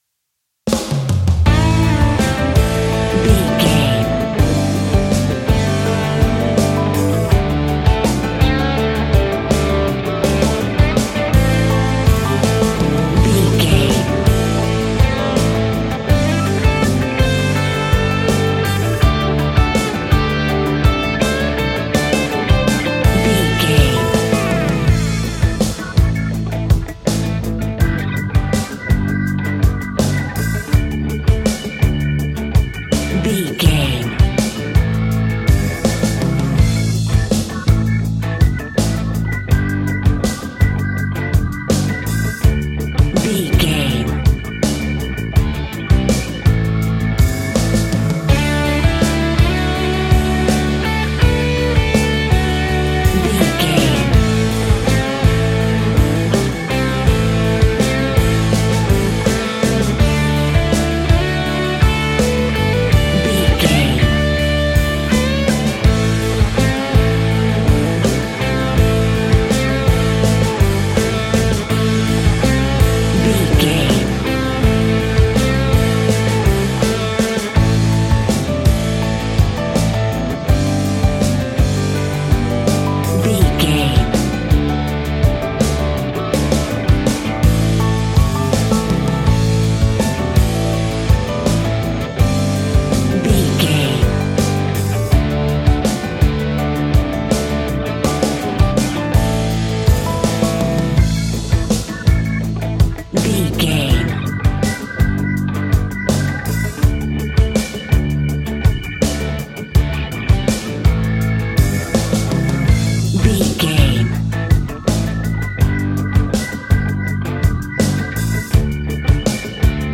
Aeolian/Minor
powerful
electric guitar
organ